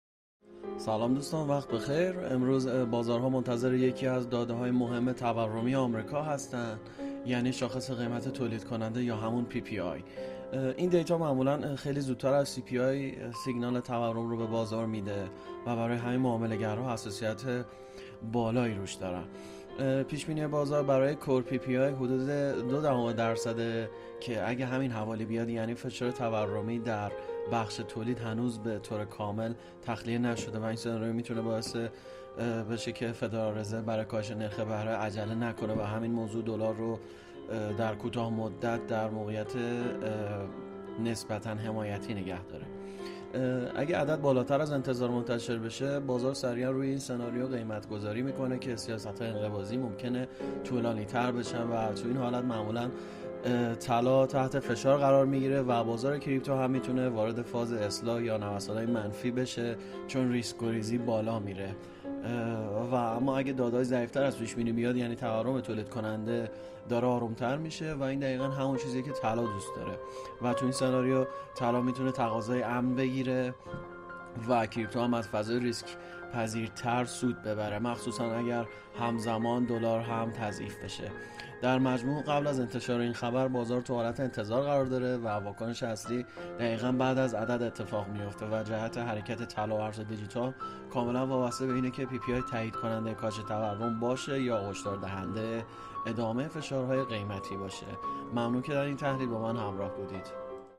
🔸گروه مالی و تحلیلی ایگل با تحلیل‌های صوتی روزانه در خدمت شماست!